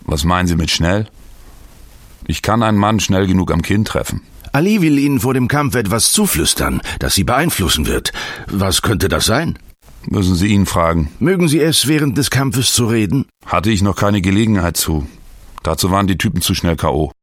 Commercial - Yello Strom